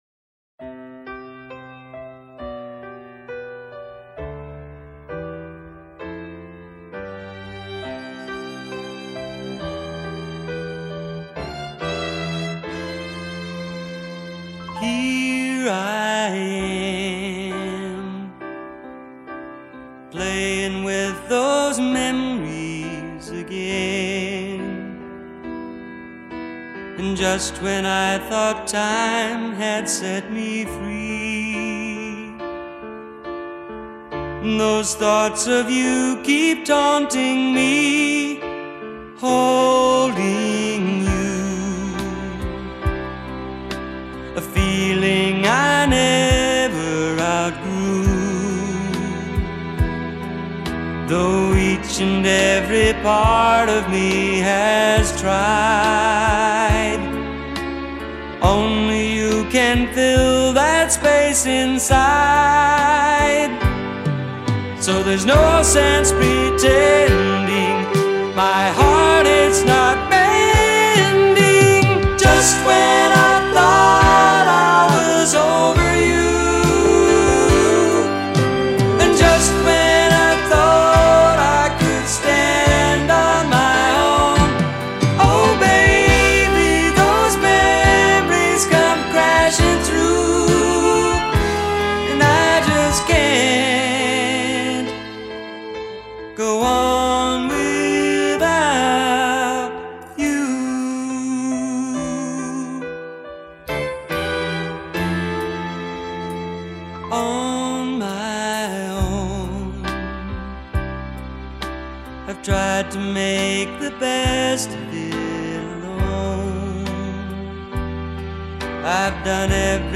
大量采用钢琴、吉它、 弦乐等音色优美的传统 乐器，因此音乐效果浪漫、抒情，非常迷人。
轻松的节奏，美妙的旋律，动人的歌词与杰出的演唱，